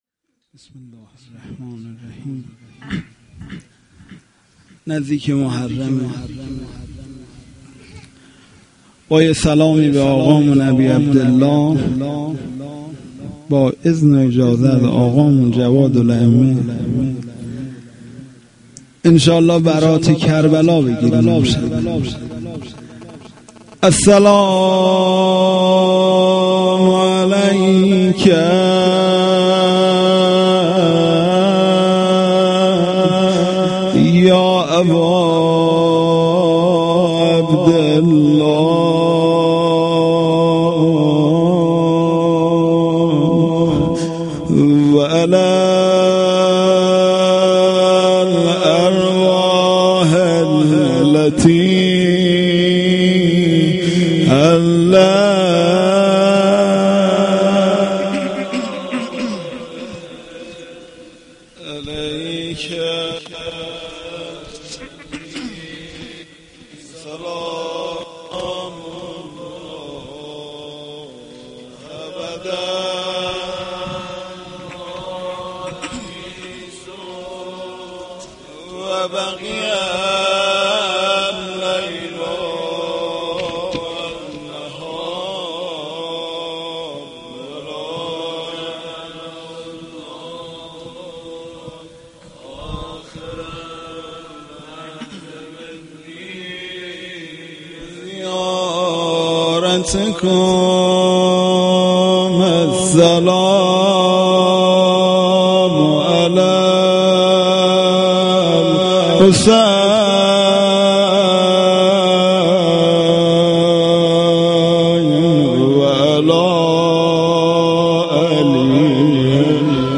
Shahadate-Emam-Javad-93-Rozeh.mp3